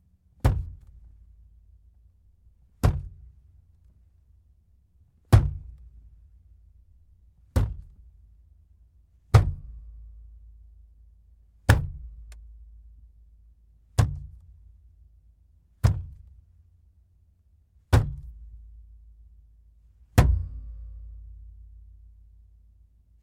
随机的 "塑料煤气罐放在人行道上满是沉重的砰砰声2
描述：塑料气体容器放在路面全重thud2.wav
Tag: 轰的一声 路面 塑料 集装箱 饱满